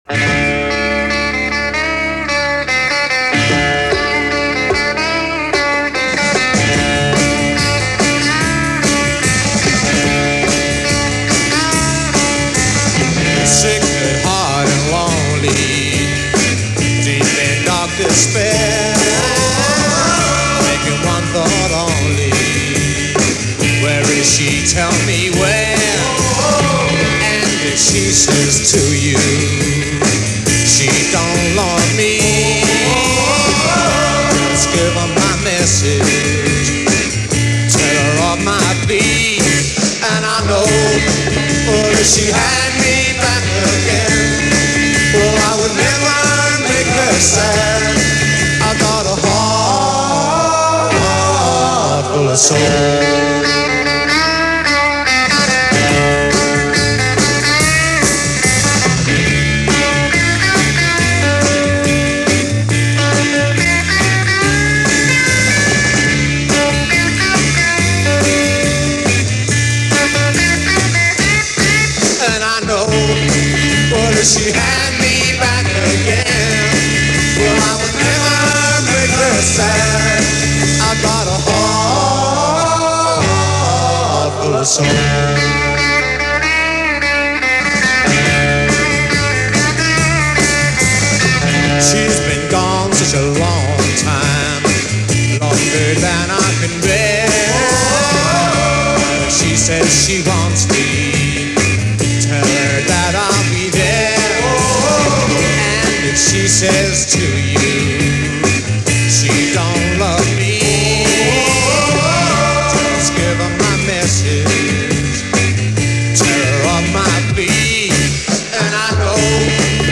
Recorded at the BBC
British Blues.